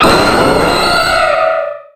Cri de Méga-Ptéra dans Pokémon X et Y.